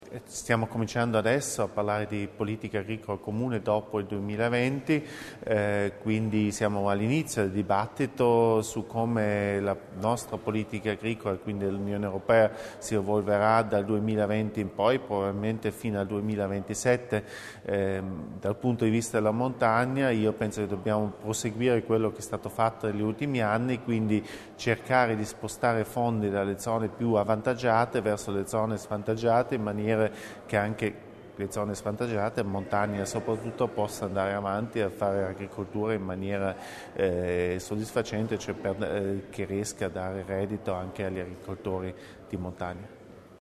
INTV_HERBERT_DORFMANN_IT.mp3